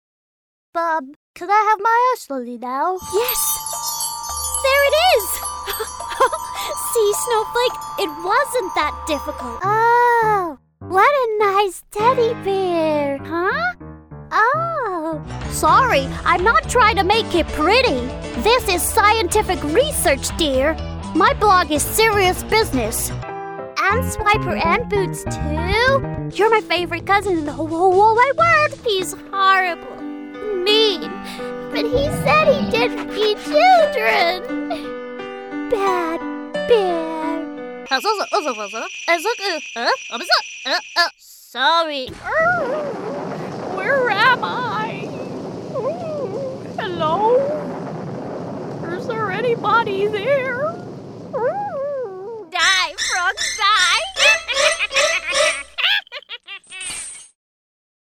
Animation Reel
• Native Accent: London
Fresh, crisp and youthful
Based in London with a great home studio.